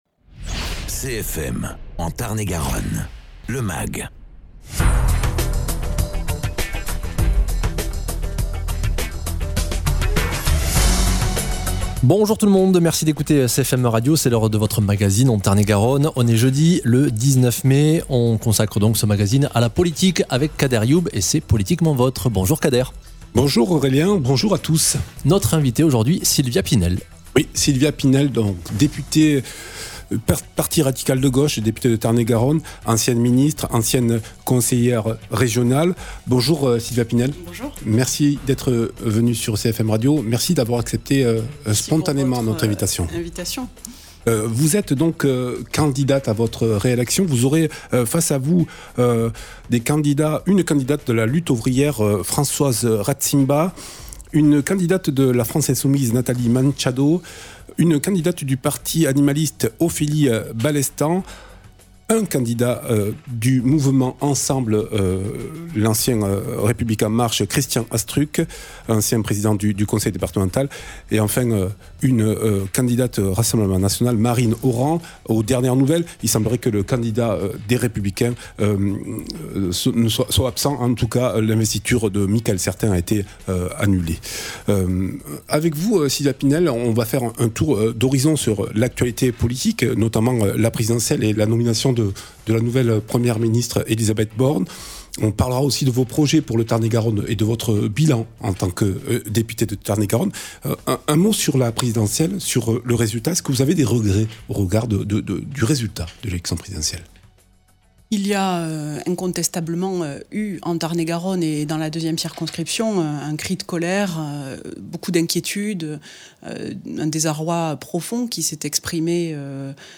Sylvia Pinel députée de Tarn-et-Garonne, candidate à sa succession était l’invitée de politiquement votre ce jeudi 19 mai. L’élection présidentielle et la nomination de la nouvelle Première Ministre, les accords de la nouvelle union populaire, les relations avec le PS, le bilan de son mandat et enfin ses projets pour le Tarn-et-Garonne.